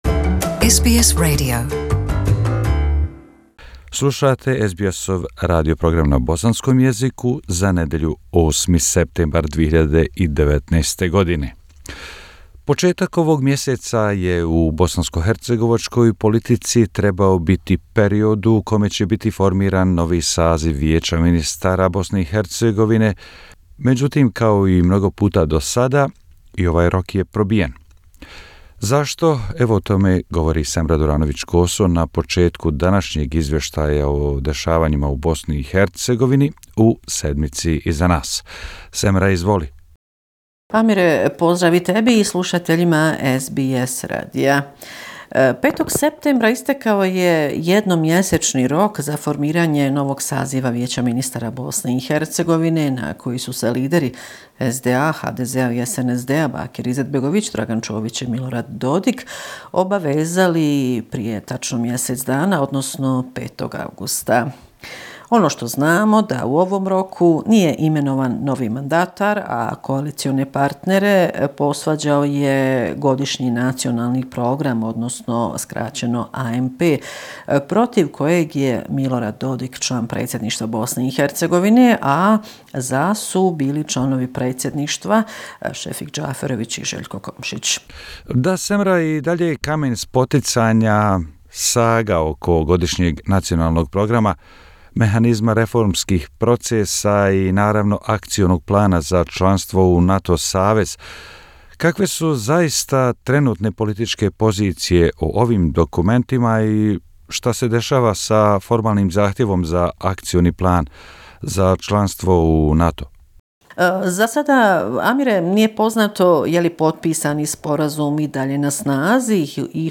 Bosnia and Herzegovina - affairs in the country for the last seven day, weekly report September 9, 2019 The deadline for the appointment of The Council of Ministers of Bosnia and Herzegovina has again not been met. Bosnia and Herzegovina capital Sarajevo divided ahead of today's Pride parade. Changes to the law on the payment of fines by foreigners before leaving the country.